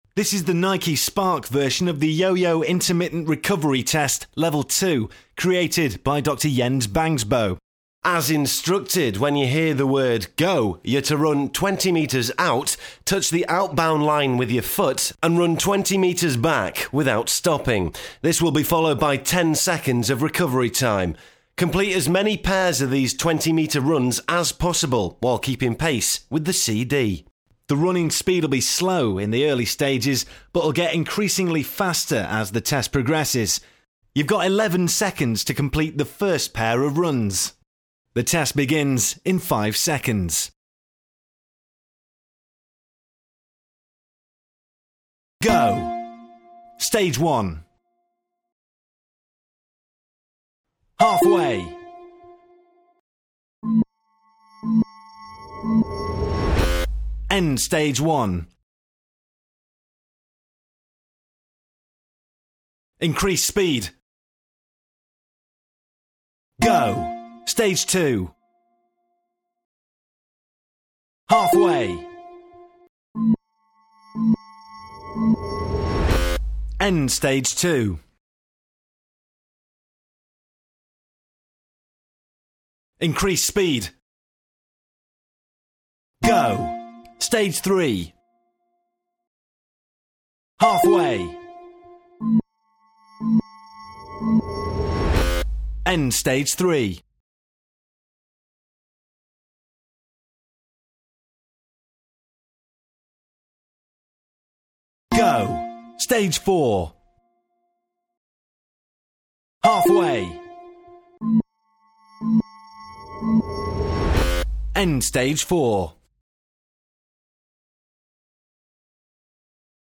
yoyo_test_level_2_male.mp3